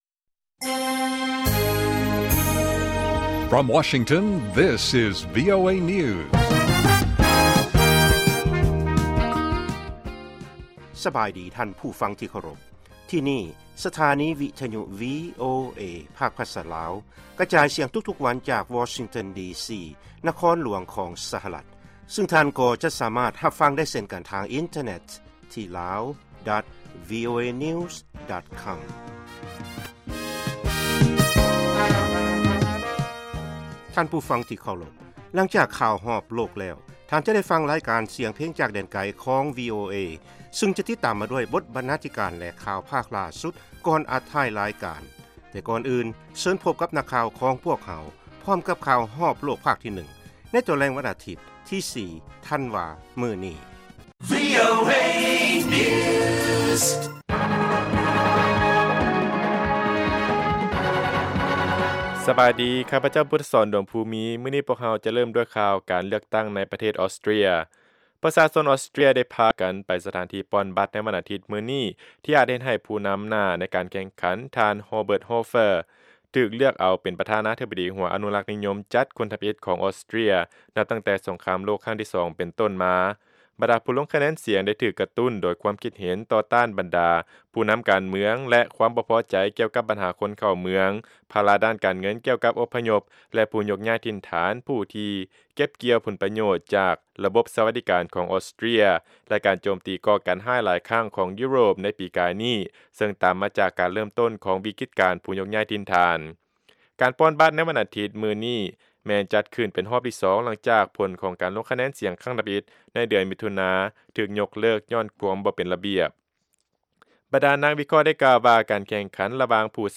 ລາຍການກະຈາຍສຽງຂອງວີໂອເອ ລາວ
ວີໂອເອພາກພາສາລາວ ກະຈາຍສຽງທຸກໆວັນ ເປັນເວລາ 30 ນາທີ.